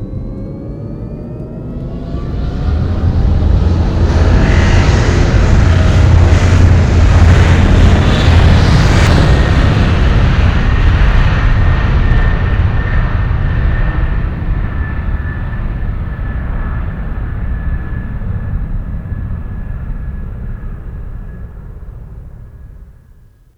takeoff.wav